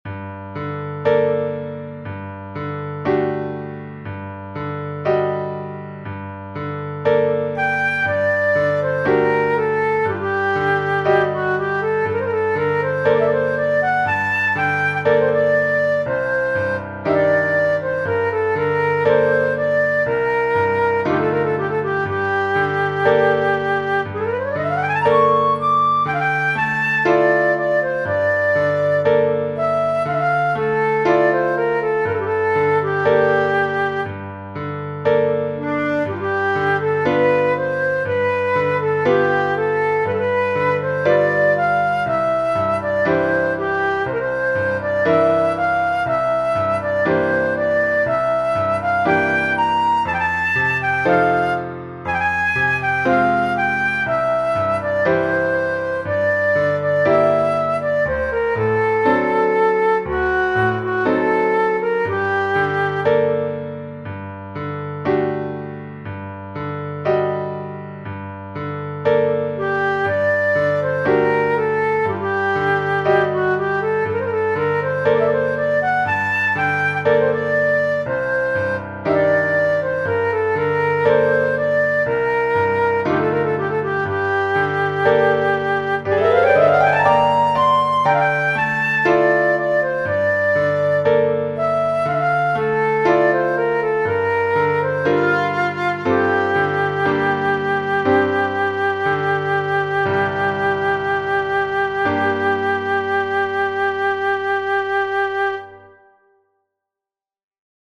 Three short compositions for flute and piano